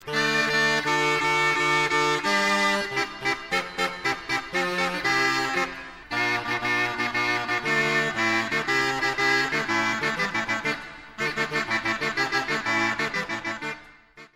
★クルムホルン
ルネサンス時代にヨーロッパで流行ったリード楽器。
即ちリードを直接くわえるのではなく、キャップの中に内蔵されたダブルリードを間接的に吹くことになる。